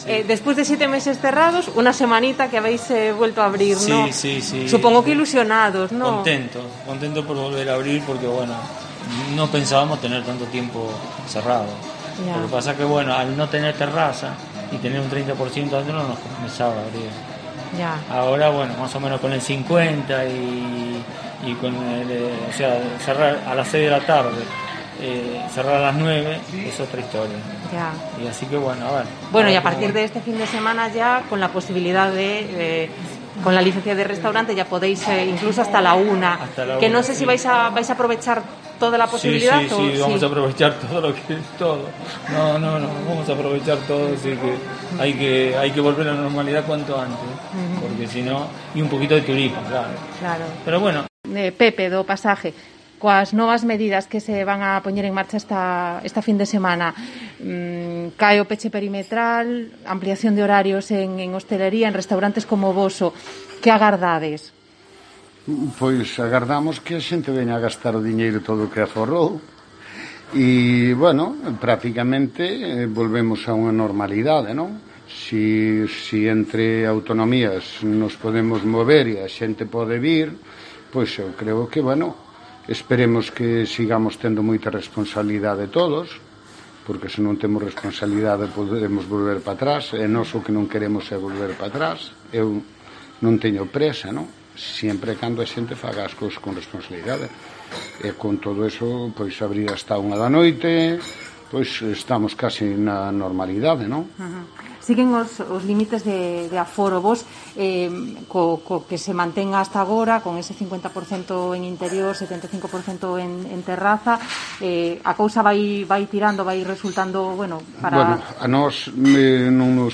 Así ven los hosteleros el nuevo escenario en Galicia